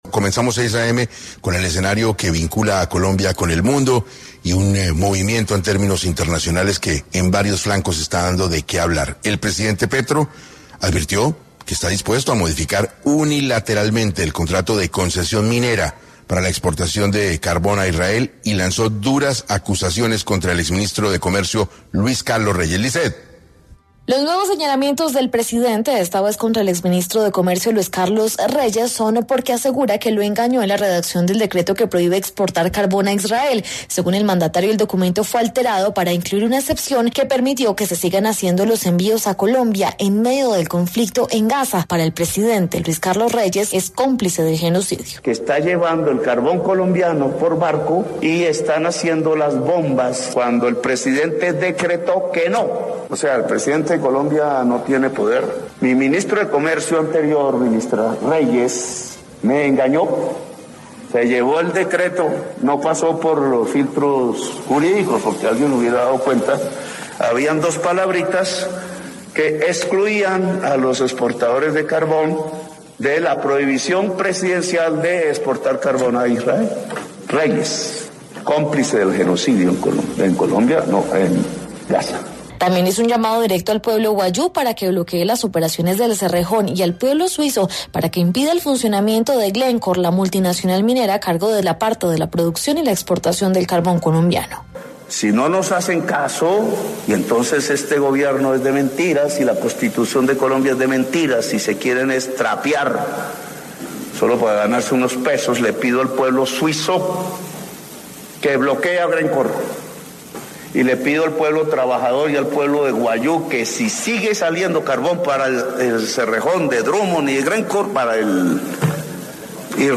Luis Carlos Reyes, exministro de Comercio, habló en 6AM sobre lo que qué hay detrás de la insistencia de Gustavo Petro de frenar la exportación de carbón a Israel.